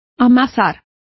Complete with pronunciation of the translation of heaping.